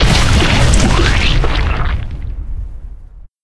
squeak_bomb_02.ogg